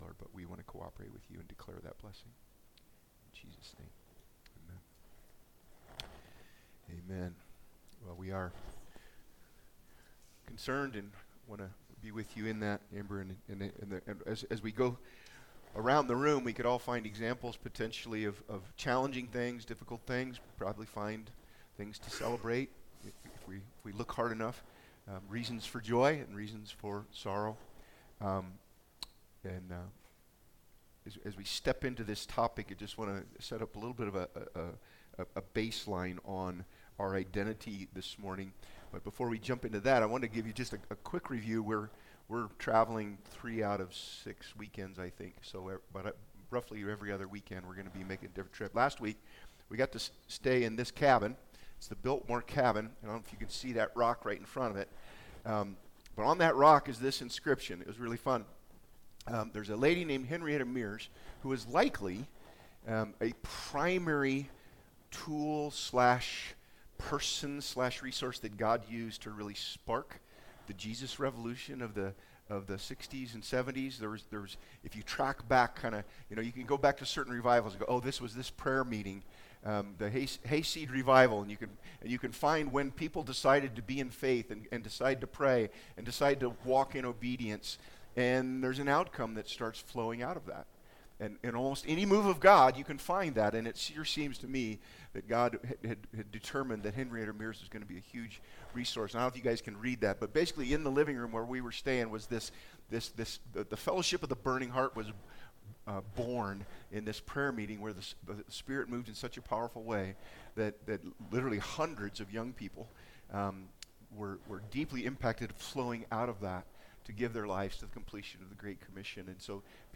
Ephesians Service Type: Sunday Topics